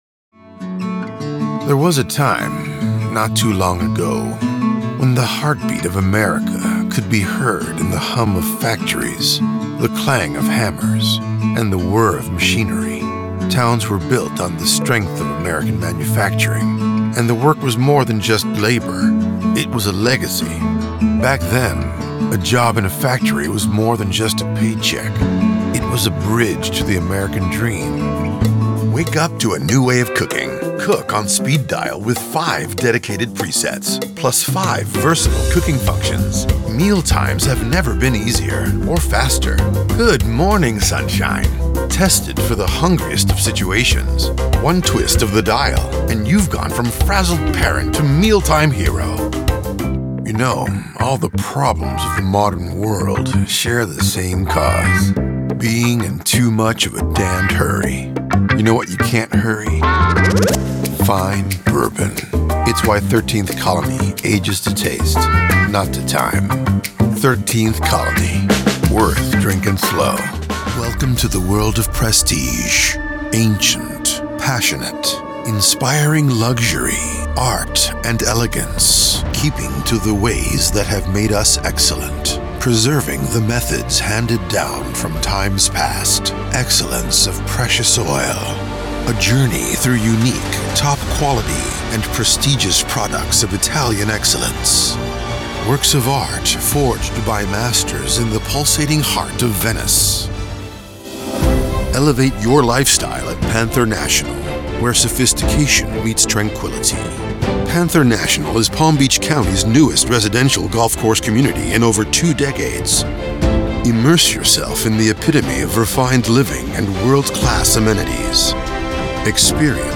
Inglés (Americano)
Profundo, Comercial, Cálida, Llamativo, Maduro
Corporativo
I have a deep, rich, voice.
All recorded in a professional grade studio with professional equipment.